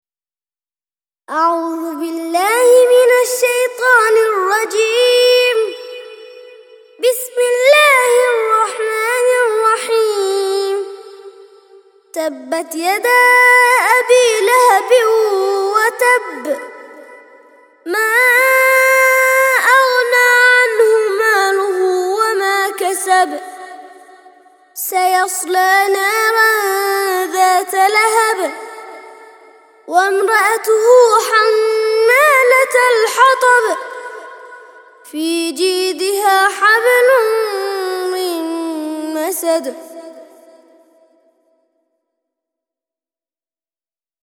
111- سورة المسد - ترتيل سورة المسد للأطفال لحفظ الملف في مجلد خاص اضغط بالزر الأيمن هنا ثم اختر (حفظ الهدف باسم - Save Target As) واختر المكان المناسب